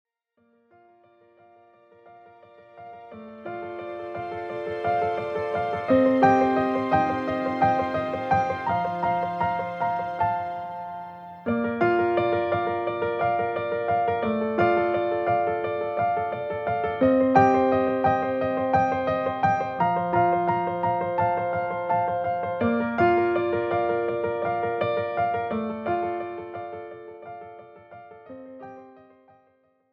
Without Backing Vocals. Professional Karaoke Backing Tracks.